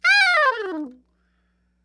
SOPRANO FALL
SOP SHRT G 5.wav